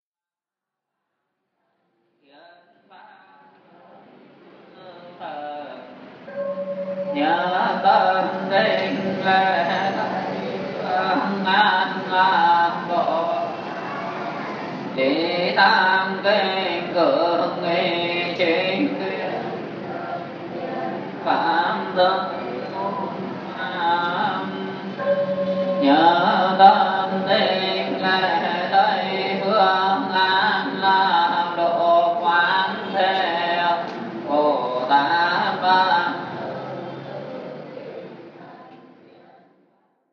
Buddhist Temple
Simple and beautiful buddhist chanting in a temple in the centre of Hanoi. The preyers participation in the singing makes it more human. It is almost like silence.
templehanoi.m4a